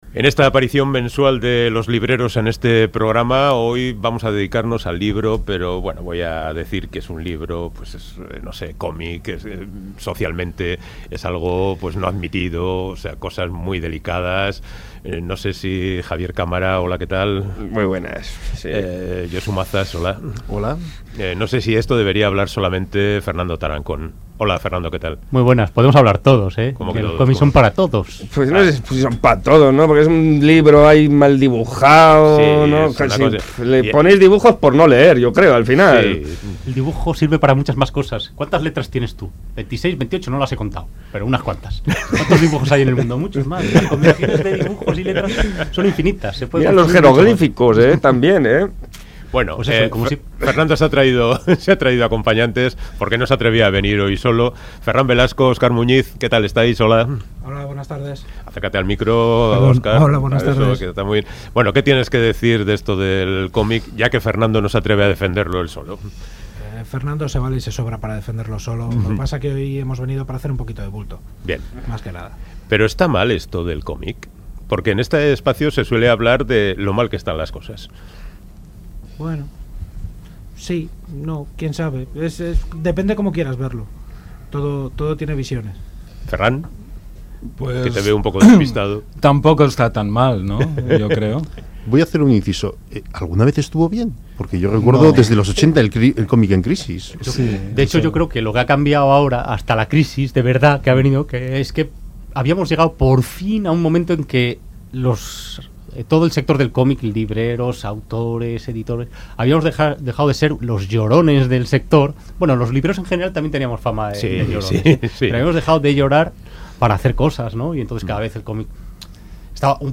Entrevista con el dibujante y guionista de comic Miguelanxo Prado